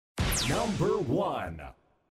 Facendo clic sul file che hai messo tra parentesi mi parte quel file audio brevissimo e sento sempre quel "Number one".